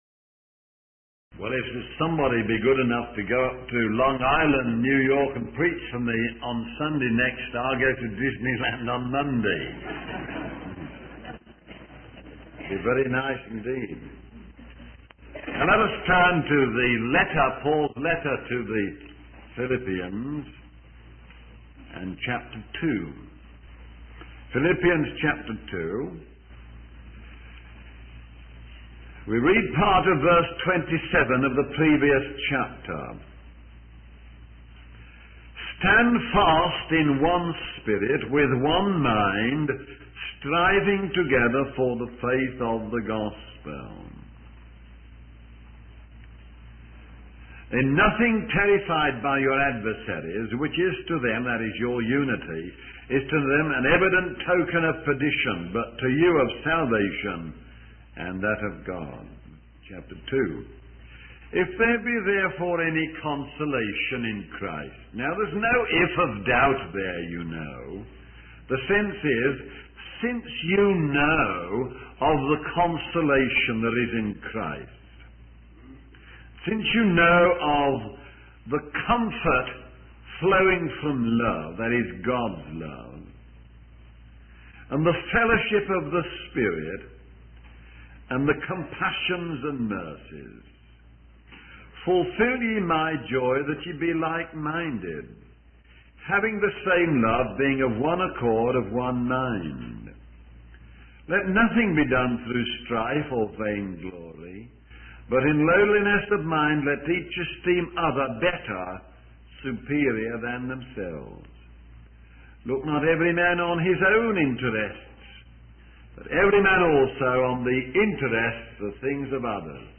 In this sermon, the speaker discusses the importance of humility and unselfishness, using the example of a friend visiting another friend who is hungry. The first friend goes to ask for food from Mr. Lord, emphasizing the need to look out for the interests of others. The speaker then transitions to discussing a passage from the Bible, where God instructs his people to confront their sins.